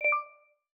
Simple Cute Alert 24.wav